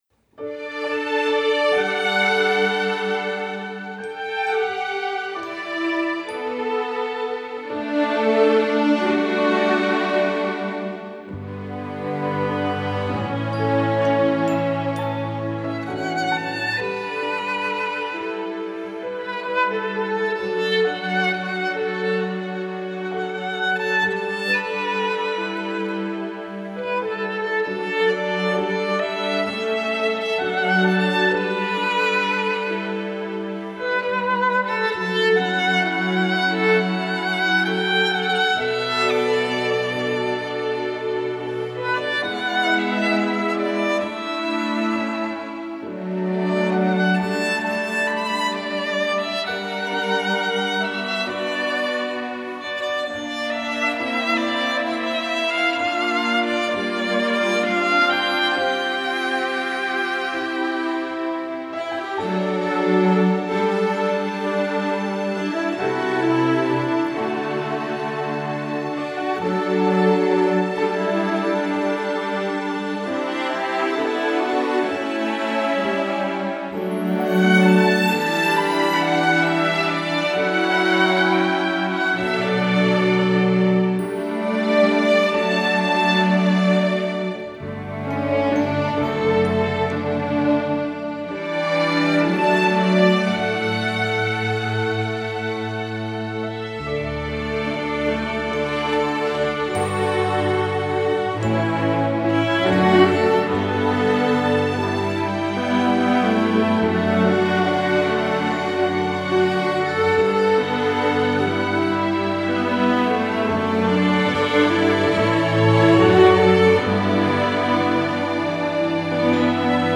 Category: String Orchestra; String Orchestra w/solo